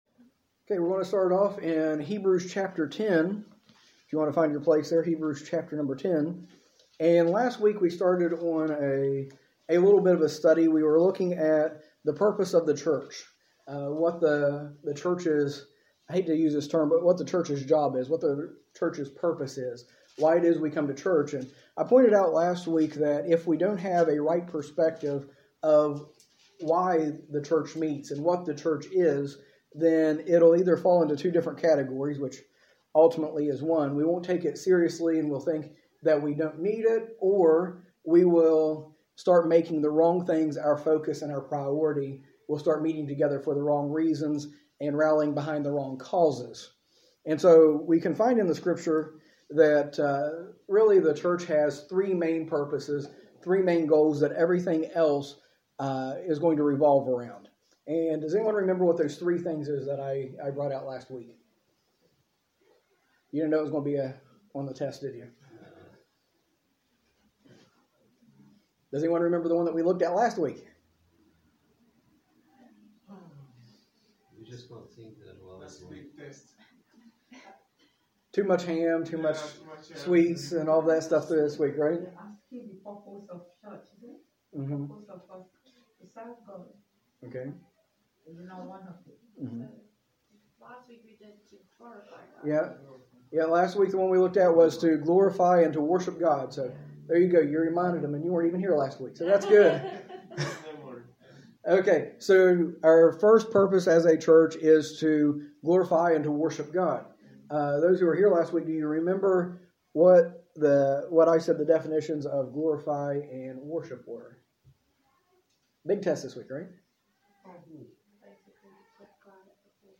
A message from the series "Practical Christianity."
In this series, taught during our adult Sunday School, we explore basic principles and teachings of Christianity that will help us live our faith in everyday life.